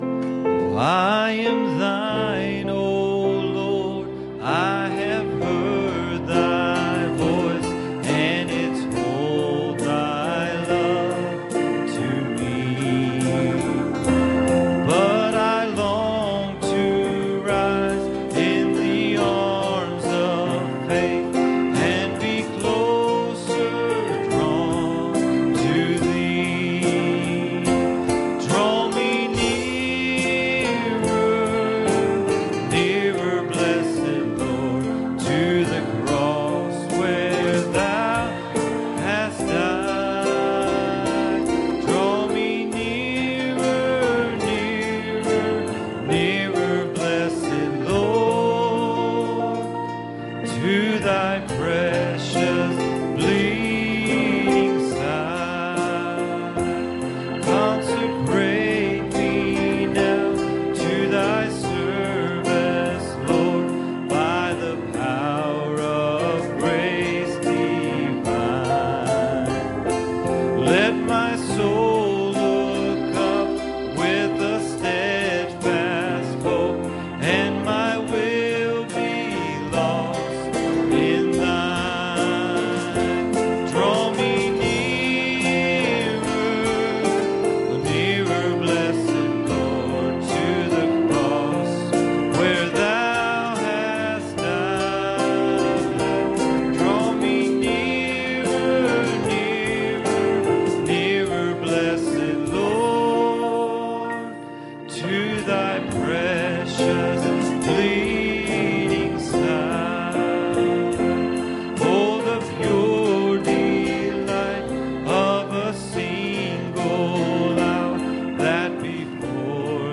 Passage: 2 John 1:5 Service Type: Wednesday Evening